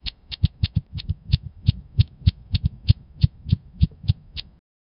Brace snapping into place with a soft metallic click Delicate flapping of repaired wings (flit-flit) Crickets playing a soft, rhythmic background hum (like nature’s heartbeat) A hush falling over the workers (faint wind gust) 0:05 Created May 16, 2025 8:52 AM
brace-snapping-into-place-ha6ujzxn.wav